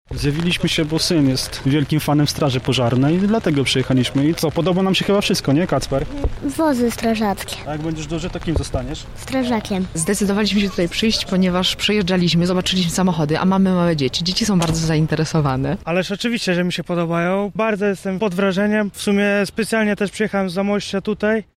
Choć aura za oknem nie dopisywała, wydarzenie przyciągnęło wielu pasjonatów straży pożarnej. To jakie wrażenie zrobił na nich prezentowany sprzęt sprawdzał nasz reporter.